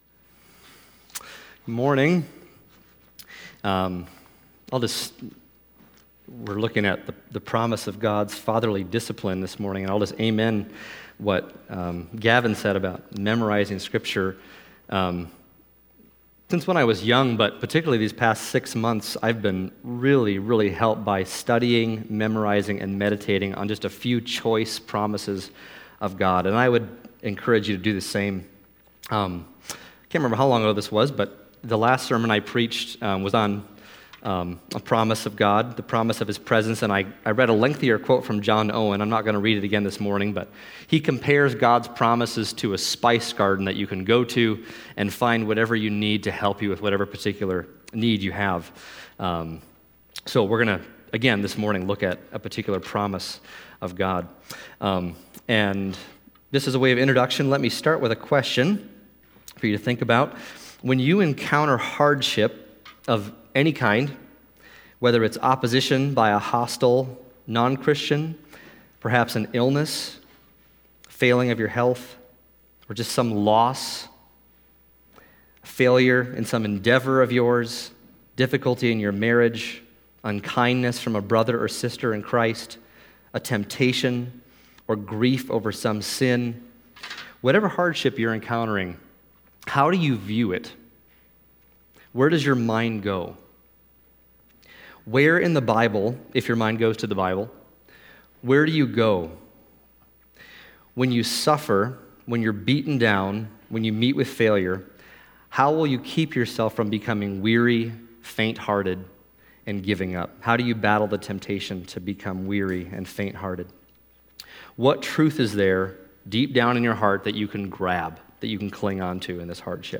Passage: Hebrews 12:1-11 Service Type: Sunday Morning